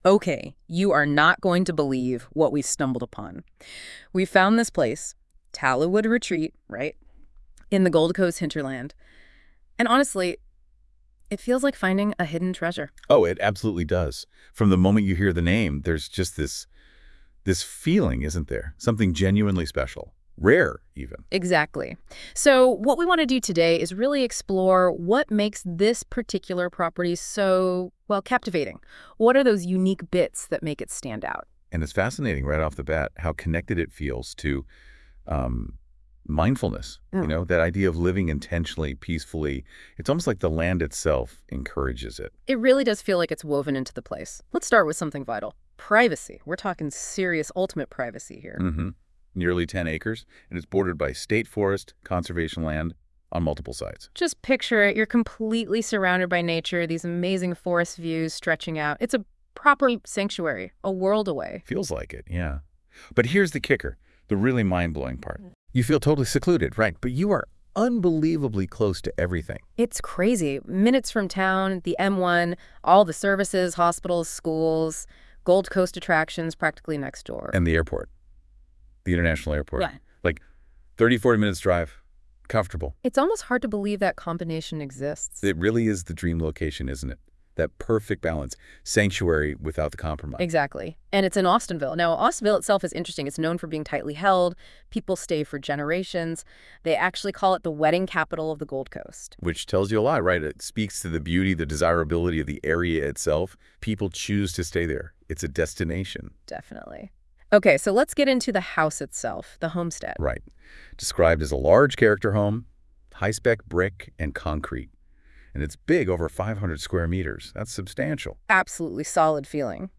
PODCAST CONVERSATION